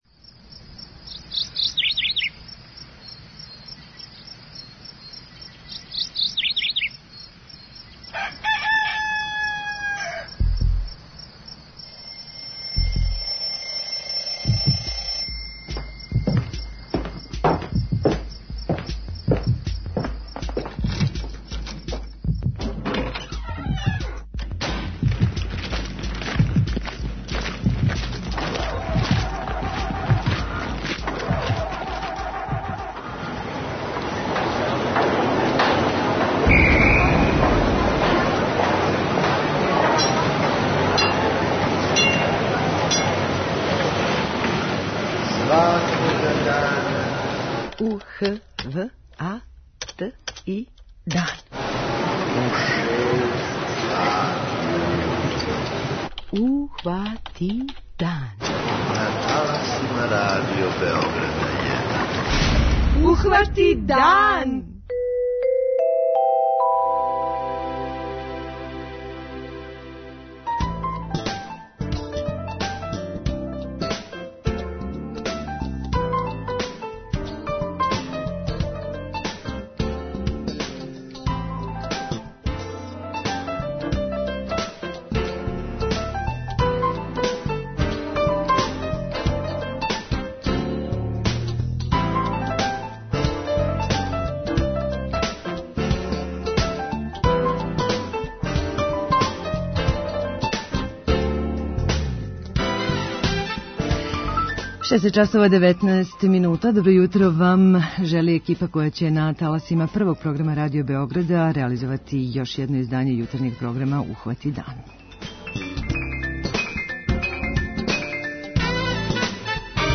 преузми : 29.23 MB Ухвати дан Autor: Група аутора Јутарњи програм Радио Београда 1!